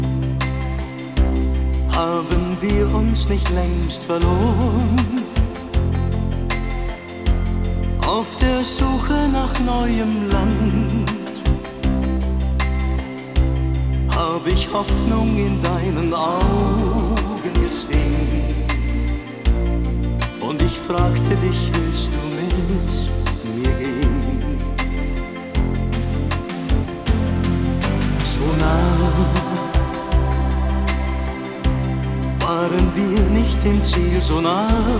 German Pop
Жанр: Поп музыка / Рок / Джаз / Классика